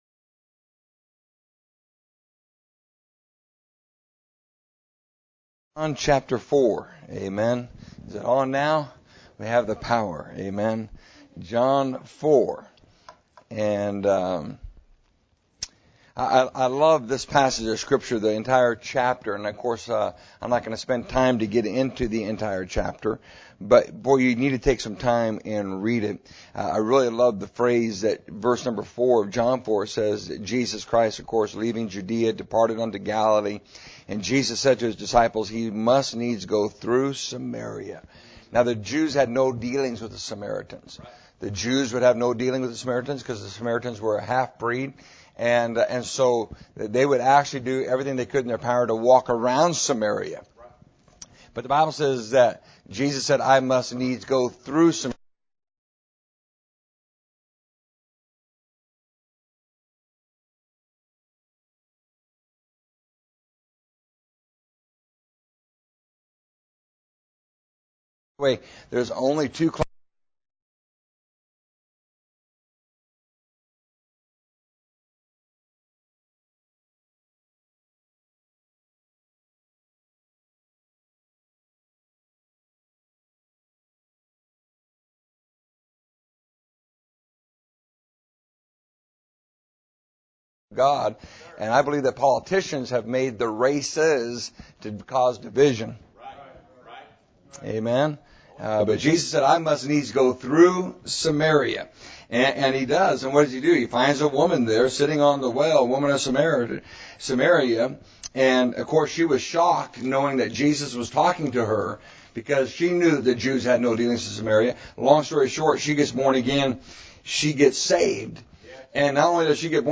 Missions Conference